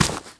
lazer_step_01.wav